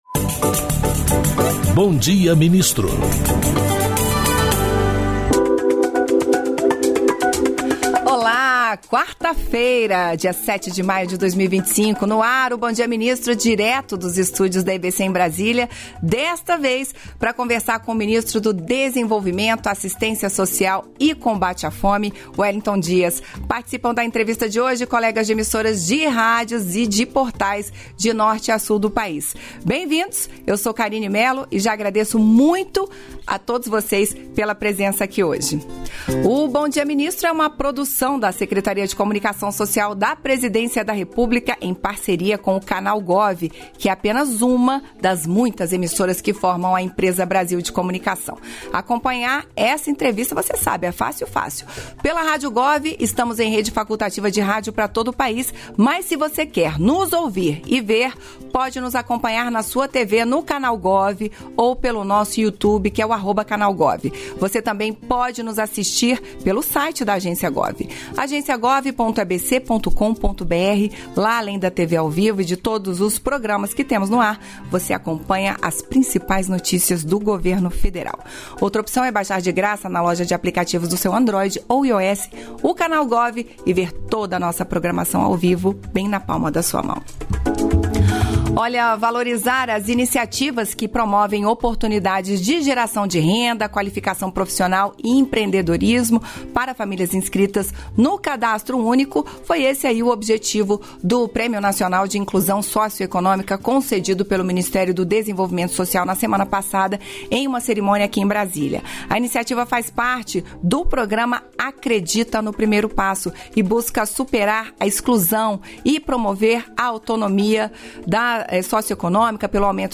Íntegra da participação ministro do Desenvolvimento e Assistência Social, Família e Combate à Fome, Wellington Dias, no programa "Bom Dia, Ministro" desta quarta-feira (07), nos estúdios da EBC em Brasília (DF).